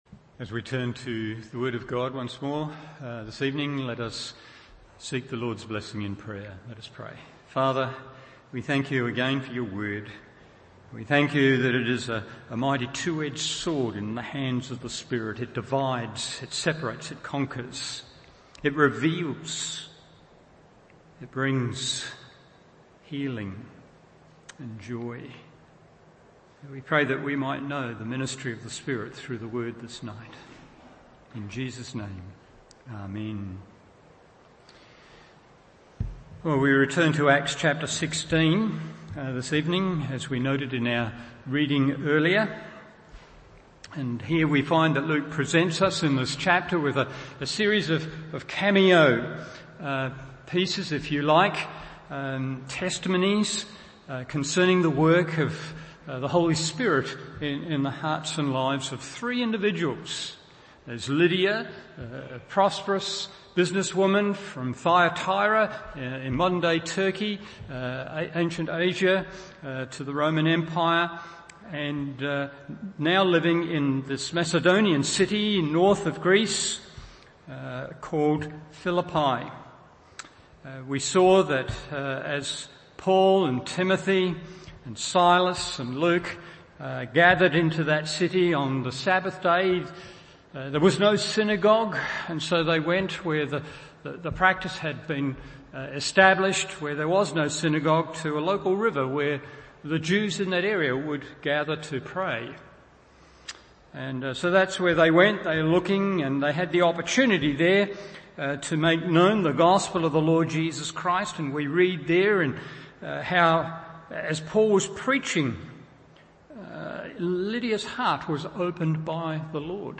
Evening Service Acts 16:16-24 1.